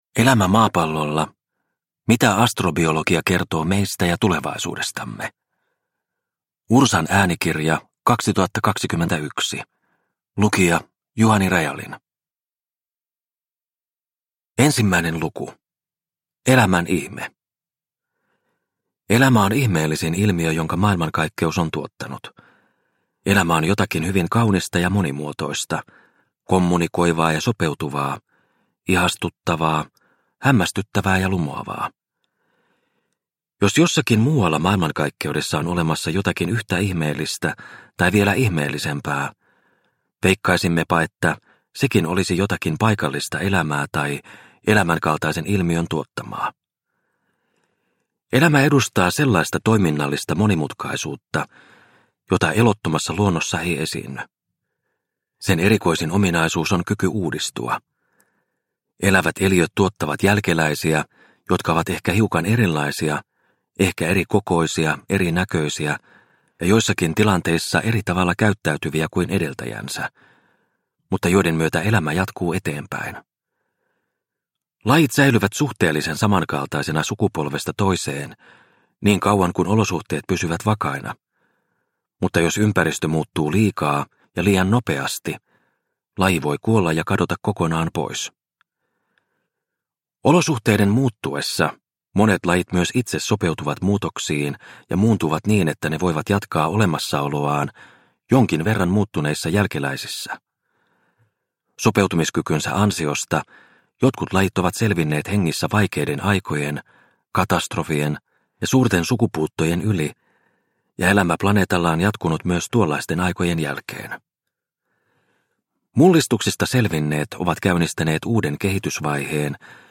Elämä maapallolla – Ljudbok – Laddas ner